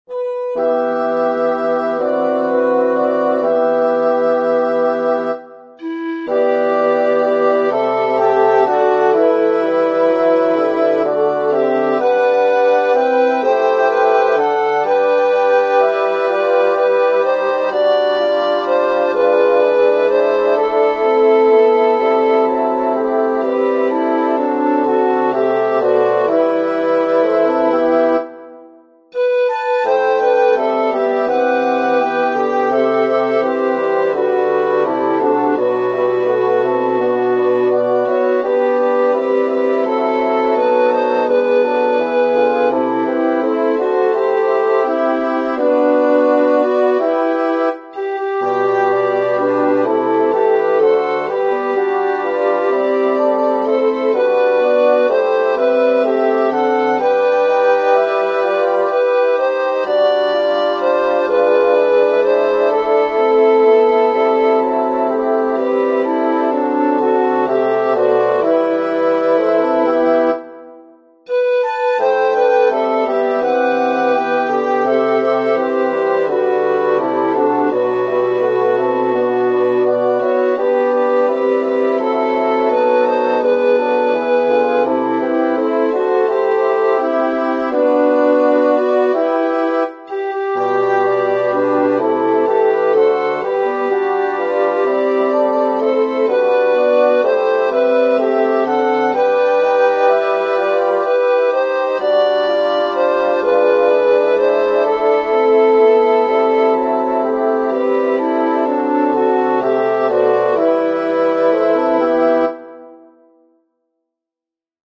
Title: Goigs a Sant Vicent Ferrer Composer: Anonymous (Traditional) Lyricist: Number of voices: 1v Voicing: S Genre: Sacred, Folksong
Language: Catalan Instruments: Organ